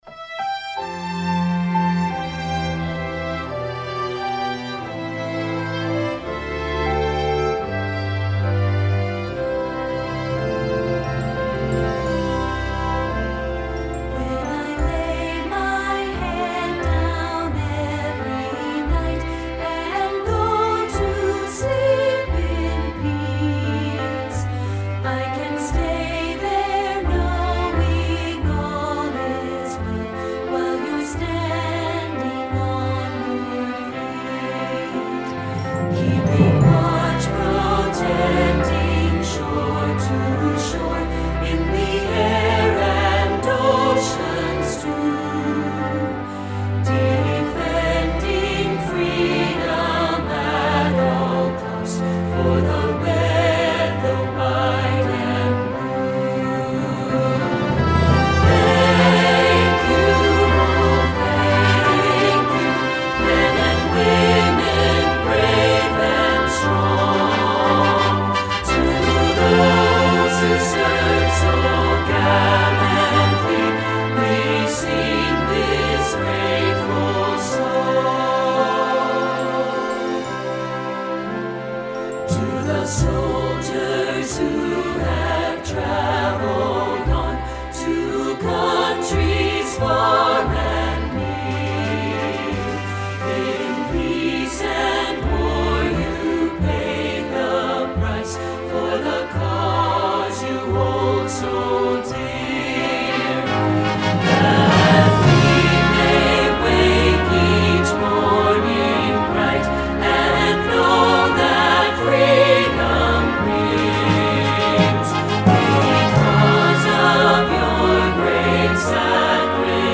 Instrumentation: full orchestra